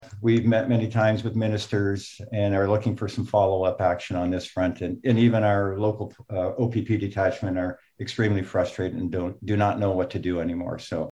Hastings County Council meeting, June 24, 2021 (Screenshot)
Mayor of Bancroft Paul Jenkins pointed out the situation is not unique to Niagara.